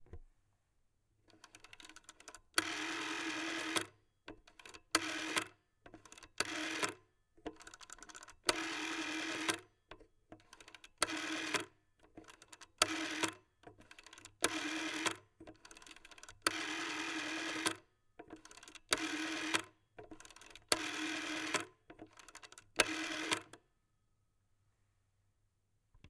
다이얼 펄스식 전화기의 다이얼을 돌릴 때의 소리